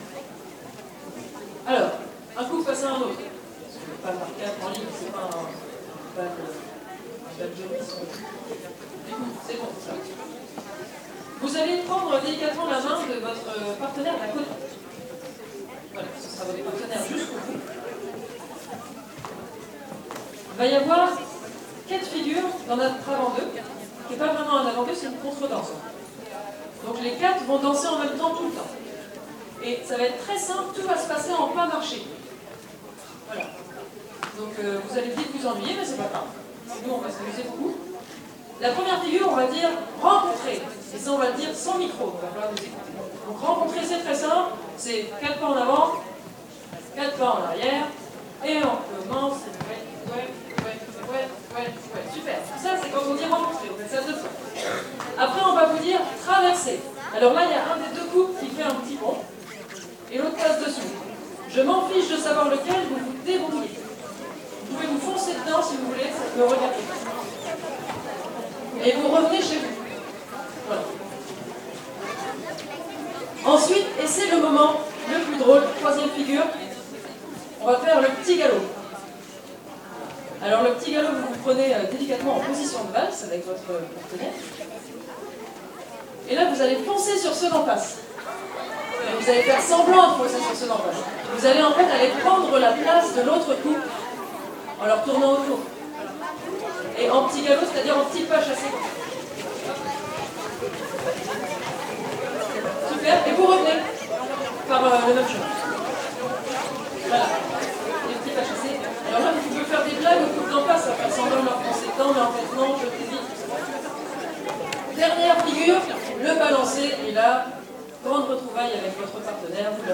08_avant-deux-avec_initiation-violons.mp3